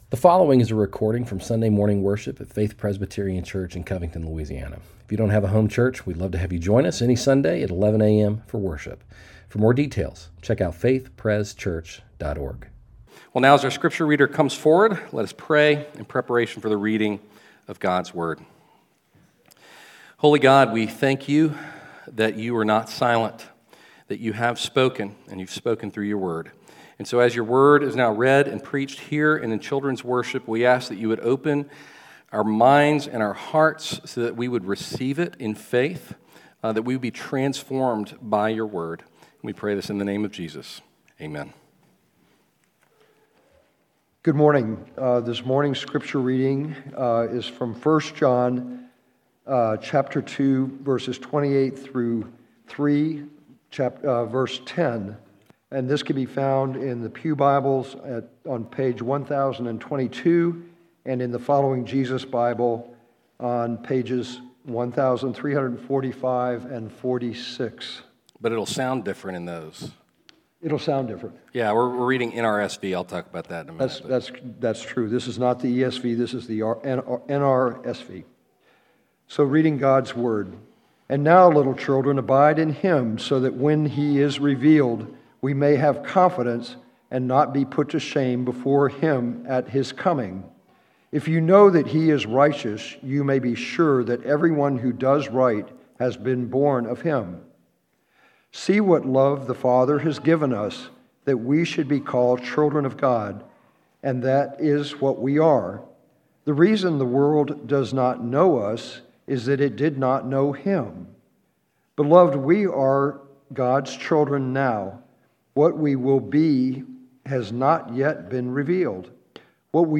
Sermons from Faith Presbyterian Church in Covington, LA